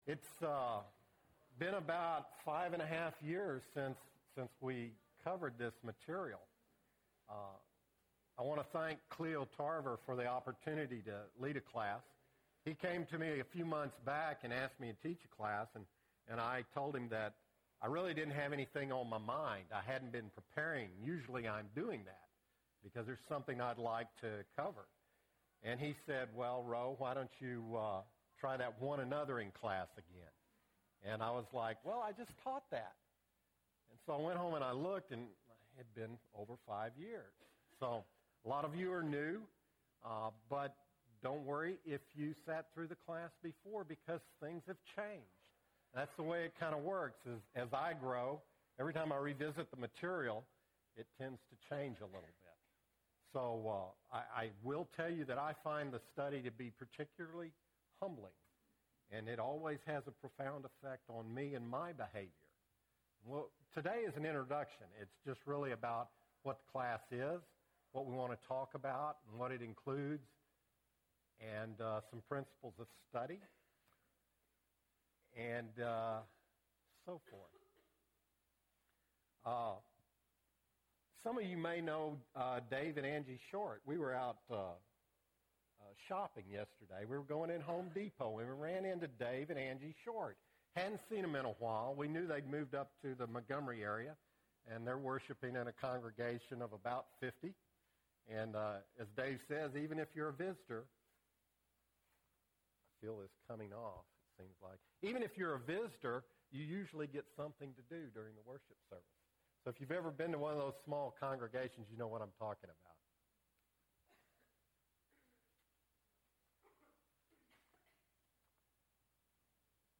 One Another-ing (1 of 13) – Bible Lesson Recording